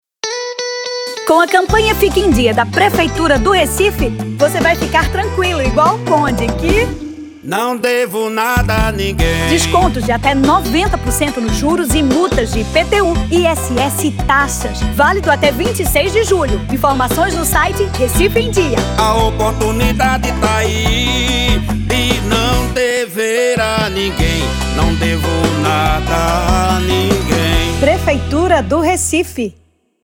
4.5 Rádio & Áudio